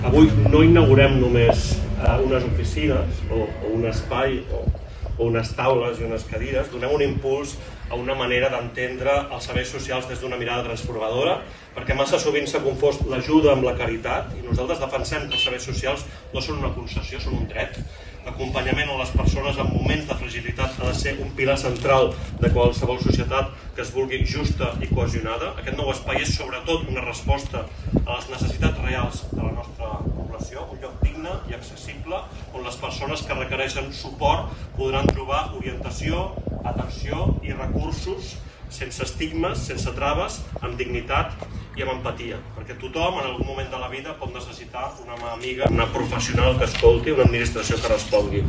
L’alcalde de Tiana, Isaac Salvatierra, va destacar com aquest nou espai s’ha creat com a resposta a les necessitats reals de la població: